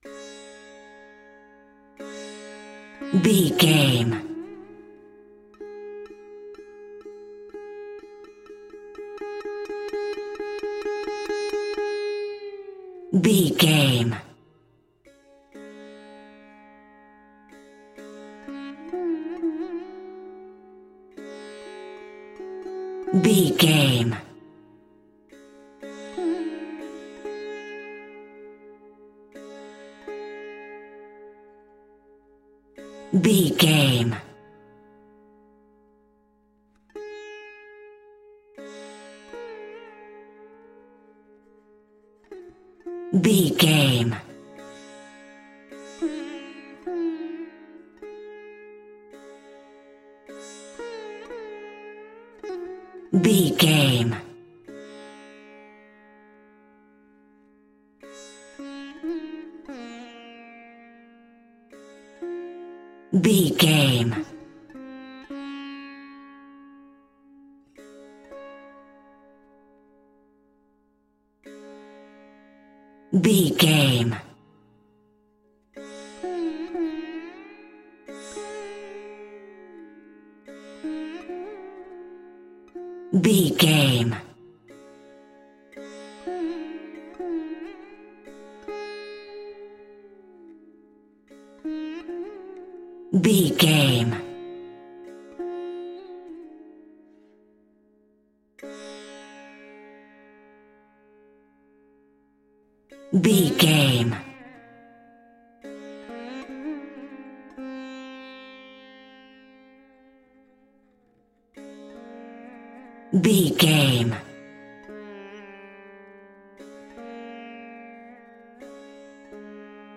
Mixolydian
D♭
Slow
World Music
percussion